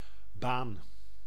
Ääntäminen
IPA : /weɪ/